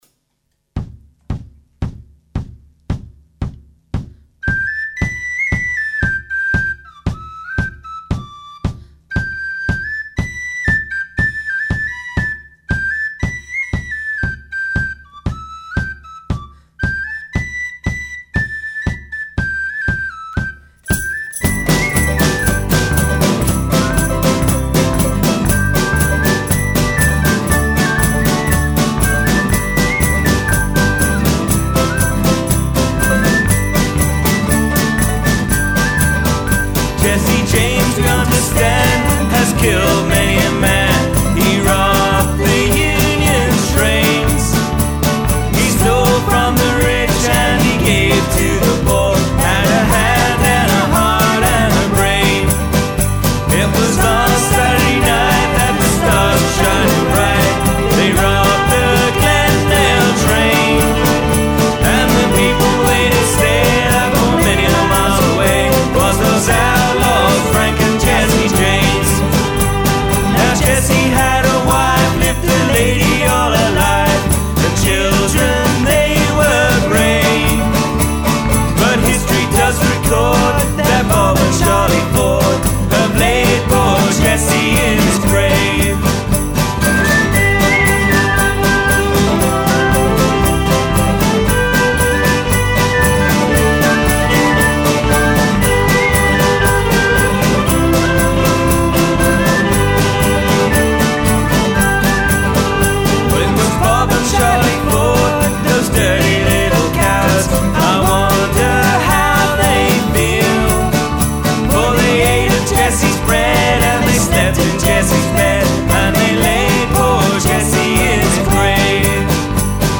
Not peak oil per se, but something my band have just recorded.
Excuse the whistle playing.